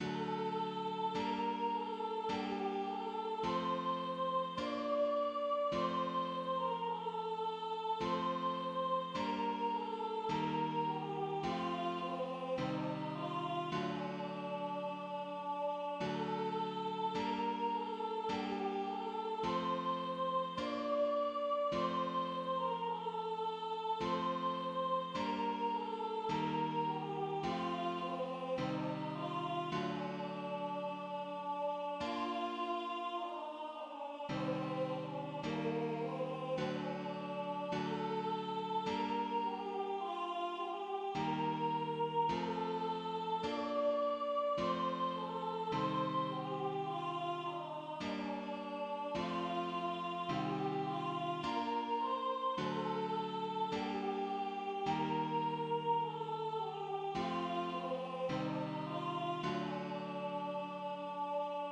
\header { tagline = ##f } \layout { indent = 0 \context { \Score \remove "Bar_number_engraver" } } global = { \key d \phrygian \time 4/4 } chordNames = \chordmode { \global \set ChordNames.midiInstrument = "acoustic guitar (nylon)" \repeat volta 2 { d,2\p g,:min | g,:min f, | bes, f,2~ | \set chordChanges = ##t f,2 \set chordChanges = ##f f, | g,:min es, | f, c,:min | d,1 | } bes,1 | c,2:min es, | d, d, | g,1:min | es,2 f, | bes, f, | c,1:min | g,2:min f, | c,:min bes, | d, g,:min | es,1 | f,2 c,:min | d,1 \bar "|." } sopranoVoice = \relative c'' { \global \set midiInstrument = "choir aahs" % "flute" \repeat volta 2 { a2 bes4 a | g a c2 | d c4.